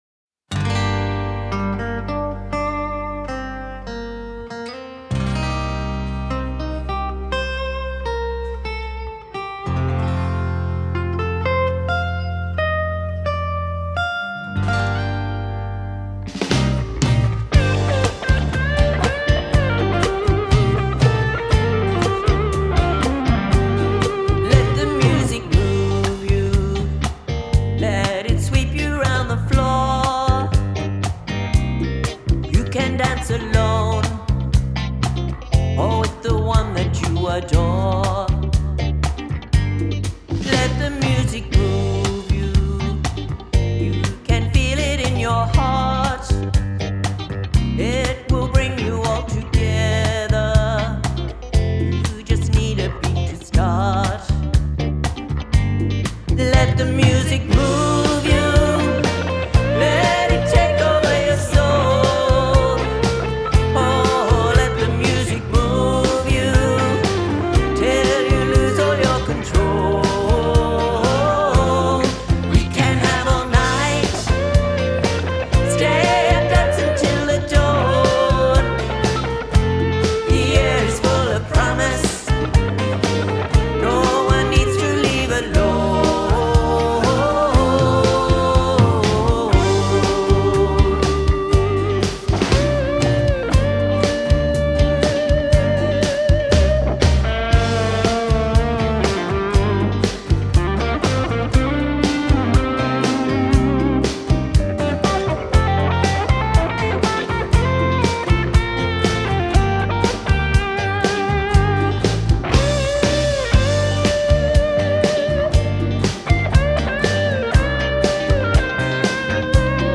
This song just begs to be danced to.